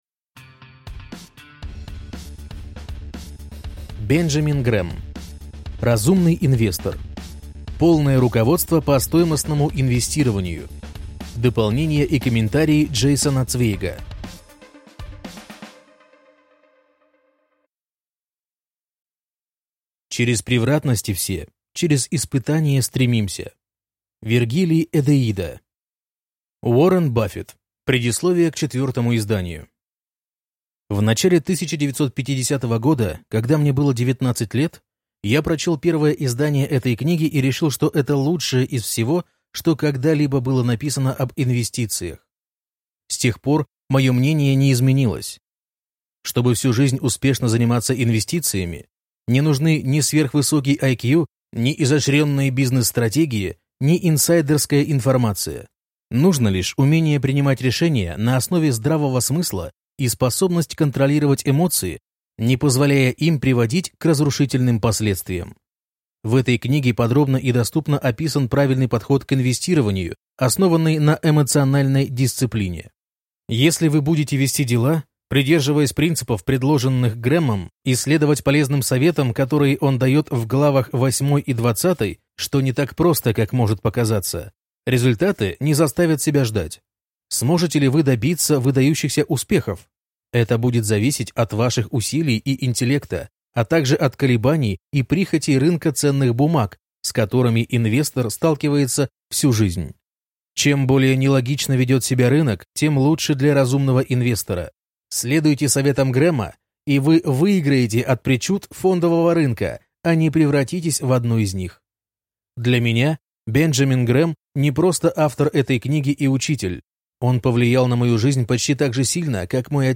Аудиокнига Разумный инвестор. Полное руководство по стоимостному инвестированию | Библиотека аудиокниг